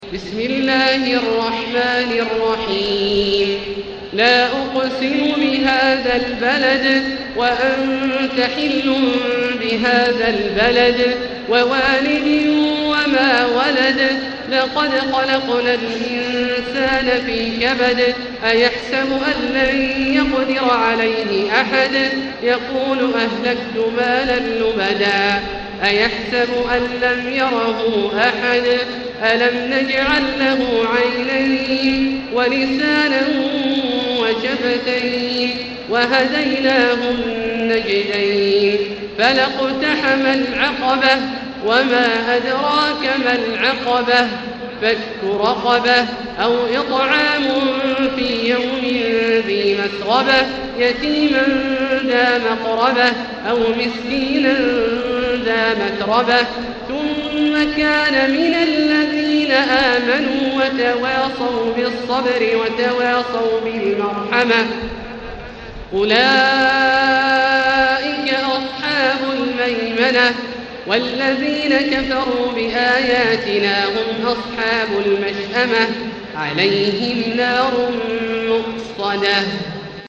المكان: المسجد الحرام الشيخ: فضيلة الشيخ عبدالله الجهني فضيلة الشيخ عبدالله الجهني البلد